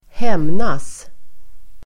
Uttal: [²h'em:nas]